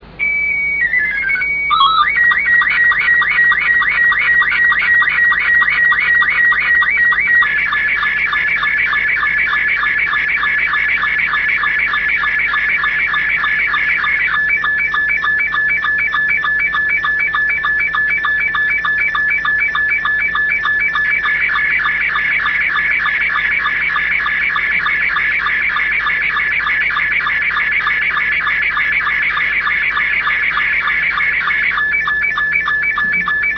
RealAudio FAX/SSTV Sounds
SSTV COLOR ROBOT 72 72 sec. 240/256